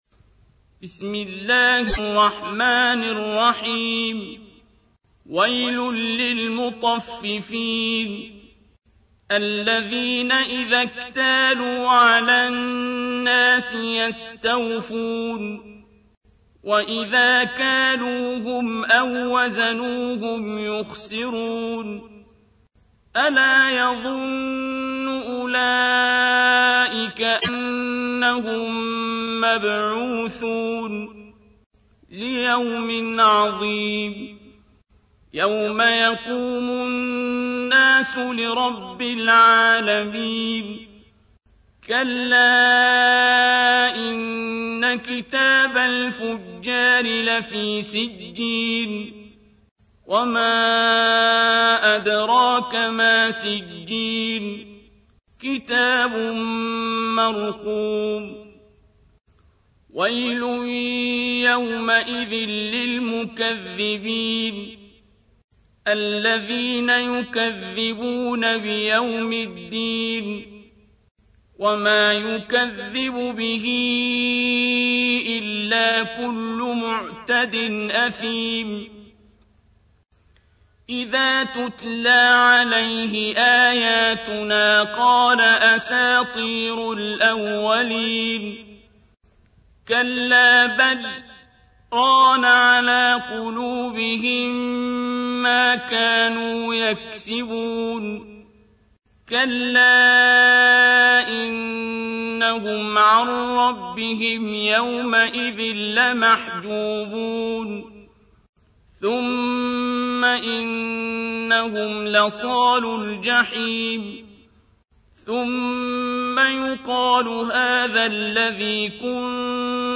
تحميل : 83. سورة المطففين / القارئ عبد الباسط عبد الصمد / القرآن الكريم / موقع يا حسين